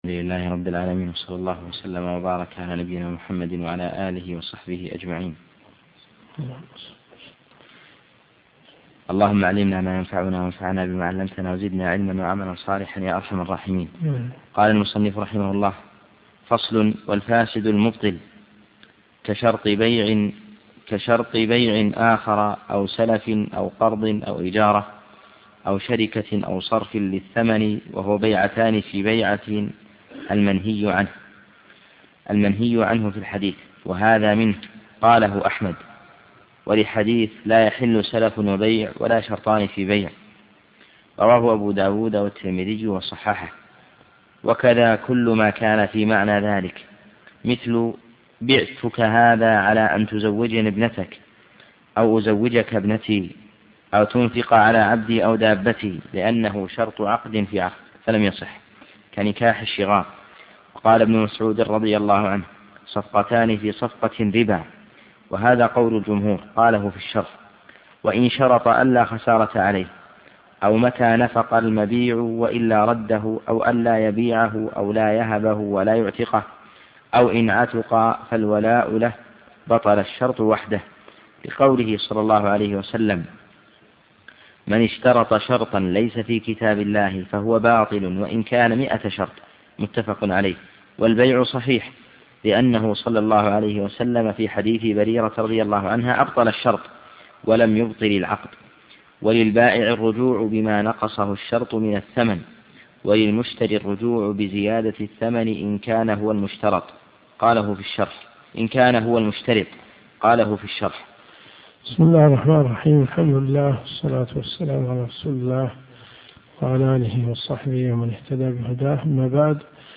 الرئيسية الكتب المسموعة [ قسم الفقه ] > منار السبيل .